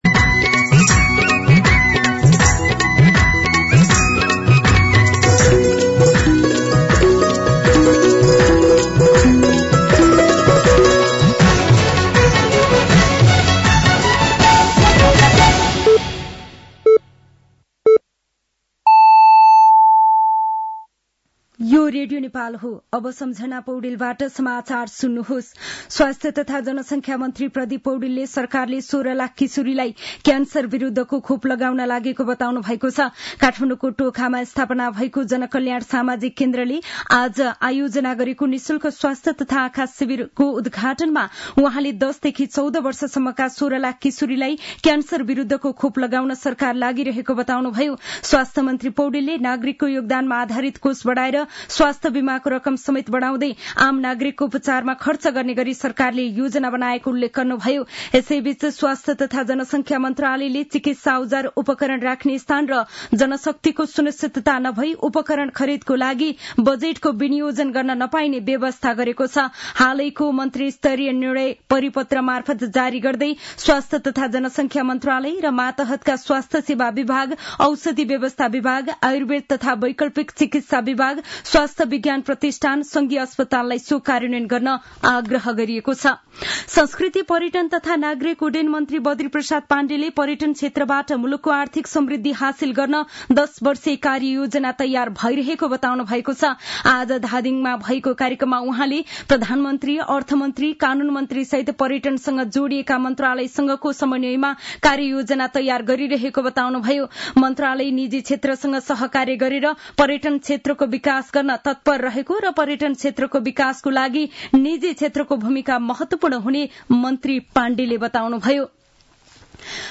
साँझ ५ बजेको नेपाली समाचार : ६ माघ , २०८१
5-PM-Nepali-News-10-5.mp3